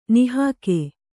♪ nihāke